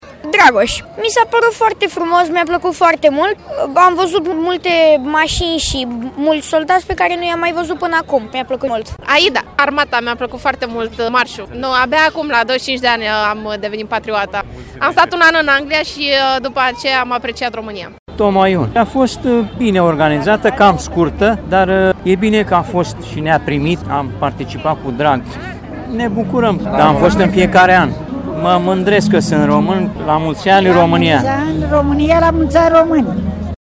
De ziua Națională a României, la 103 ani, bucureștenii au asistat la parada organizată la Arcul de Triumf.